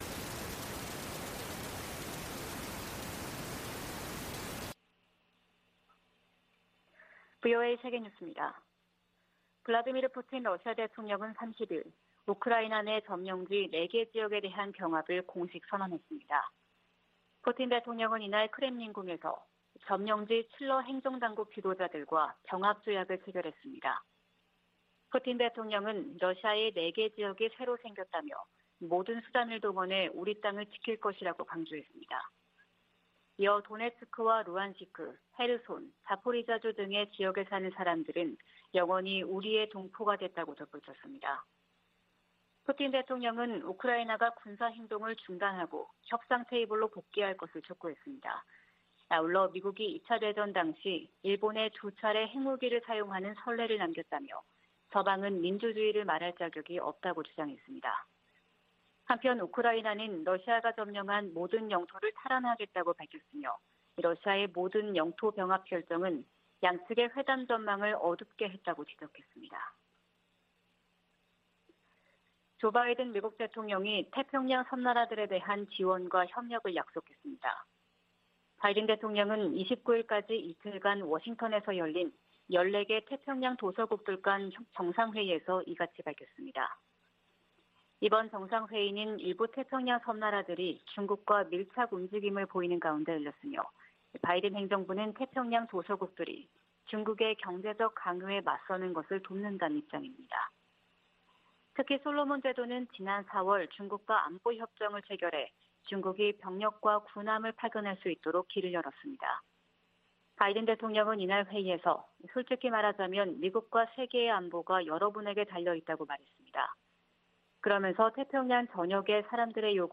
VOA 한국어 '출발 뉴스 쇼', 2022년 10월 1일 방송입니다. 백악관은 카멀라 해리스 부통령이 한국에서 미국의 확장억제 의지를 재확인했다고 밝혔습니다. 북한이 닷새 간 세 차례 탄도미사일 도발을 이어가자 한국 정부는 국제사회와 추가 제재를 검토하겠다고 밝혔습니다. 미한일이 5년만에 연합 대잠수함 훈련을 실시하고 역내 도전에 공동 대응하기로 했습니다.